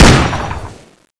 Index of /server/sound/weapons/tfa_cso/hk121_custom
fire_1.wav